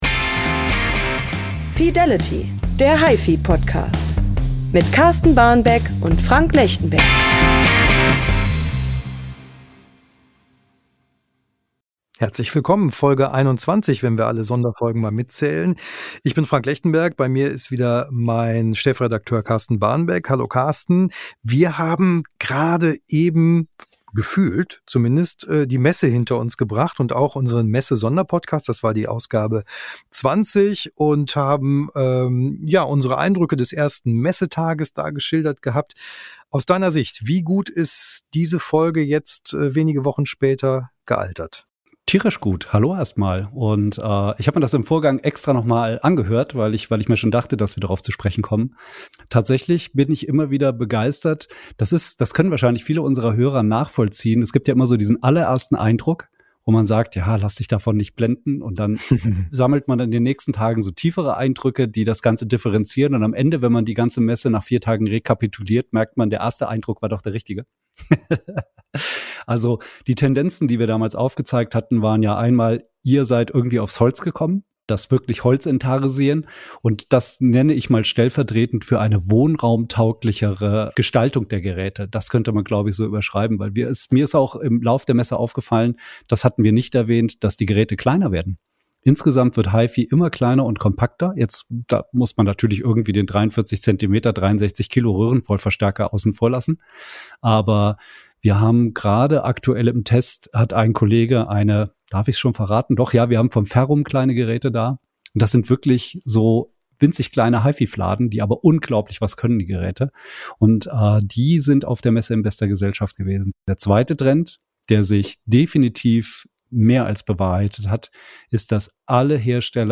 Geräte, Gerüchte und gute Musik! Der gepflegte Talk am Kaffeetisch zu unserem liebsten Hobby.